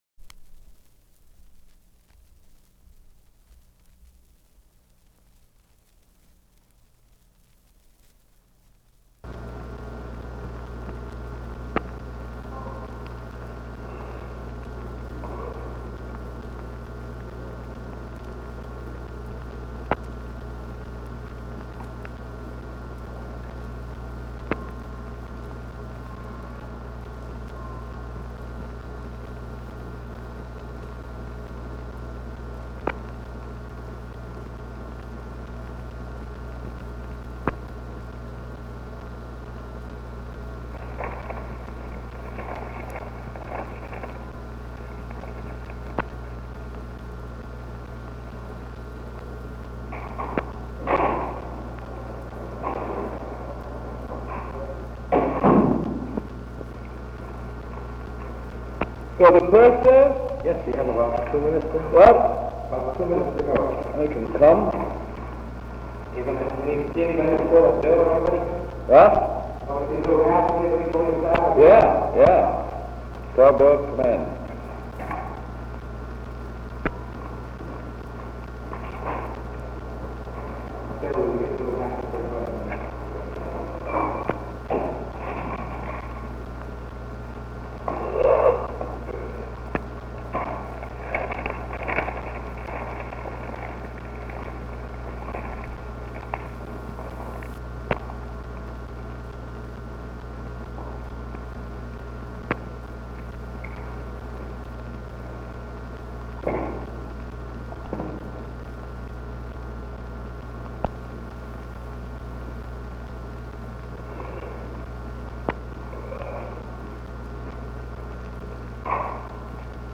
Press Conference 682
Secret White House Tapes | Franklin D. Roosevelt Presidency